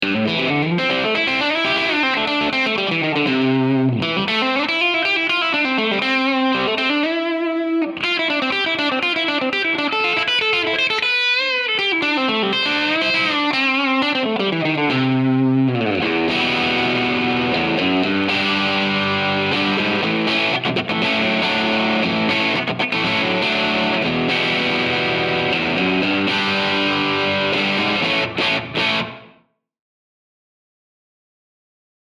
This Vintage Tele lead pickup uses alnico 2 rod magnets to provide a softer treble attack for players who want Telecaster tone without excess bite.
APTL-1_DIRTY_SOLO_SM